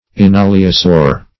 Enaliosaur \En*al"i*o*saur`\, n.